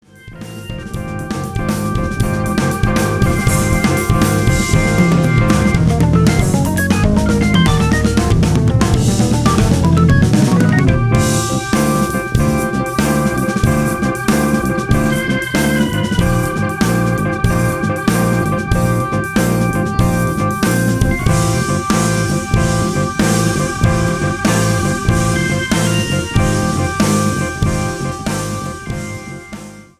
とはいえ曲想はまったく違い、こちらは4つ打ち8ビートを基調とする複雑なリズムのロック・ナンバー。重厚なリズムに乗って